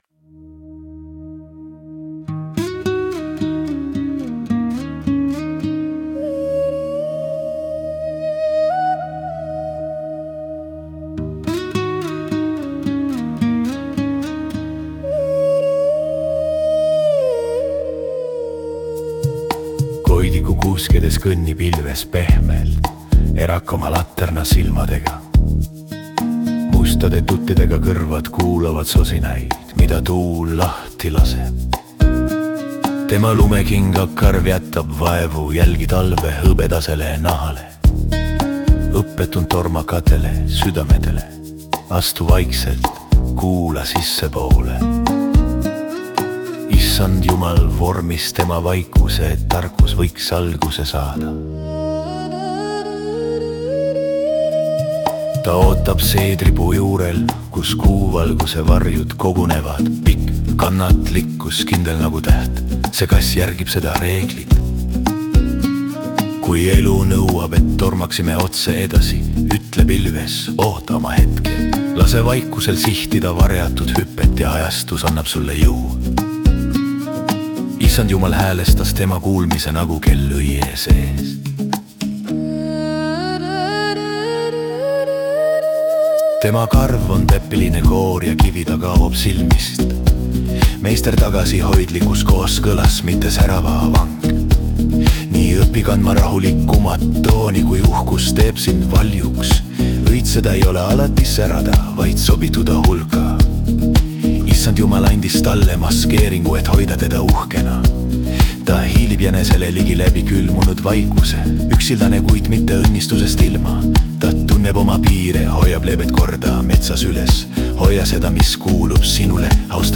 energizing beat